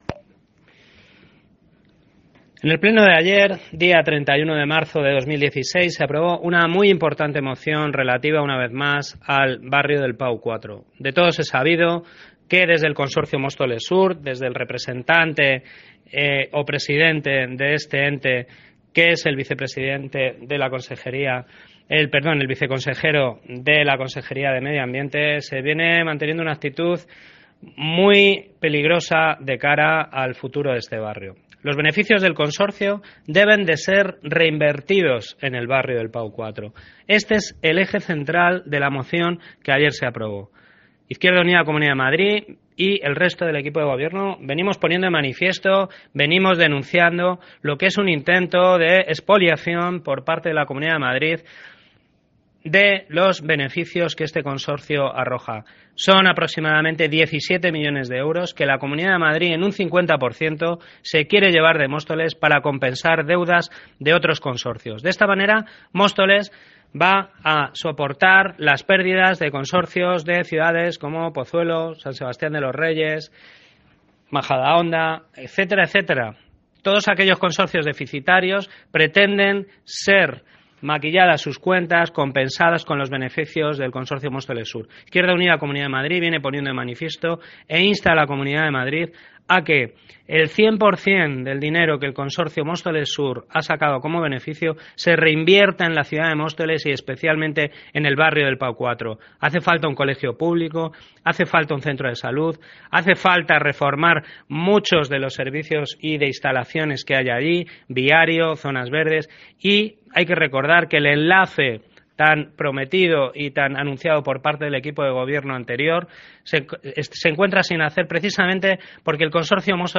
Audio - Eduardo Gutiérrez (Concejal de Urbanismo y Vivienda) Aprobación moción beneficios Móstoles sur